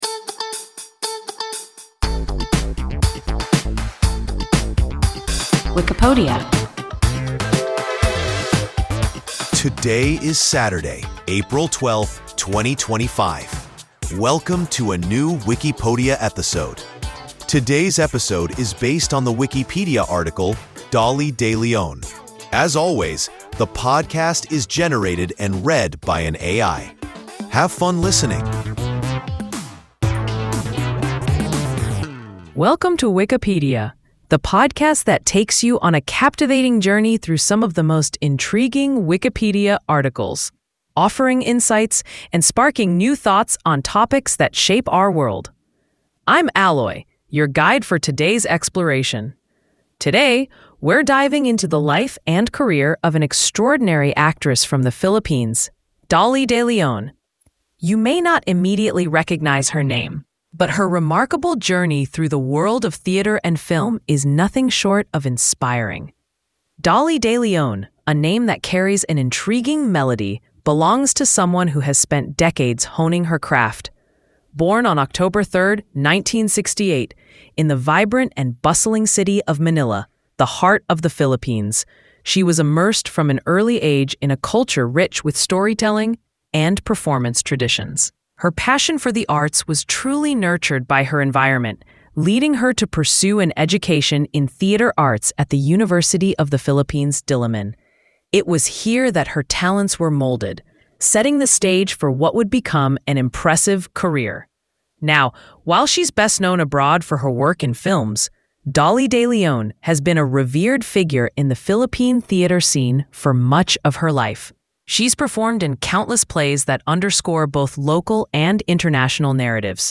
Dolly de Leon – WIKIPODIA – ein KI Podcast